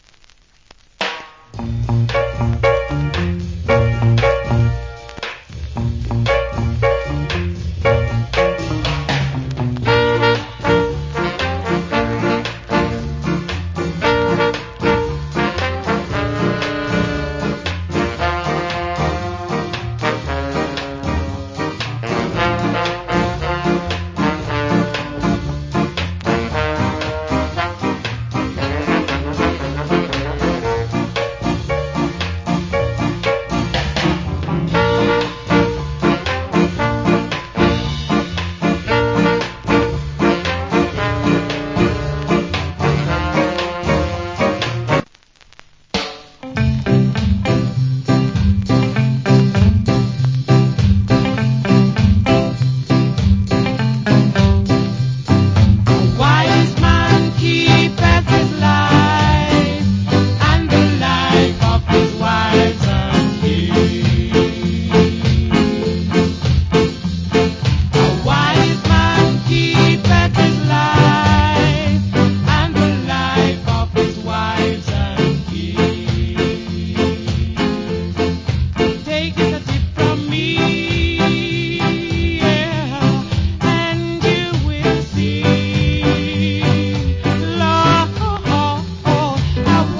Cool Up Tempo Rock Steady Inst.